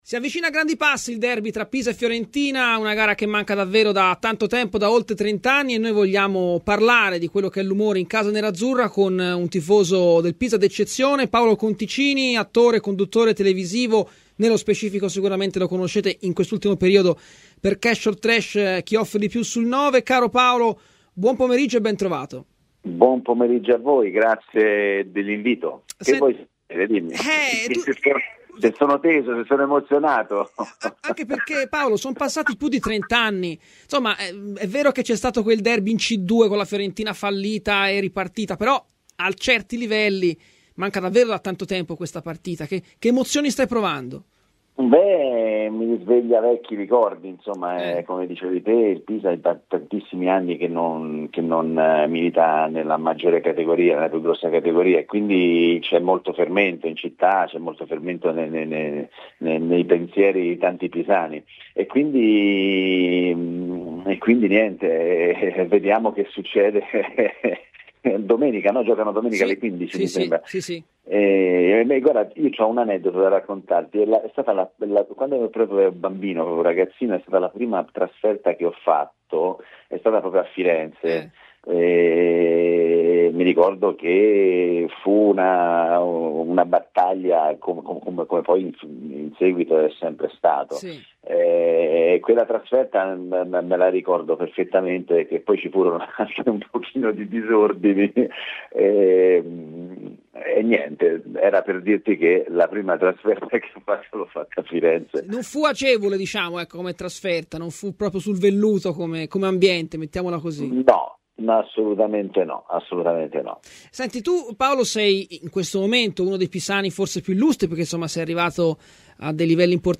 Paolo Conticini, noto attore e tifoso del Pisa, ha parlato ai microfoni di Radio Firenzeviola.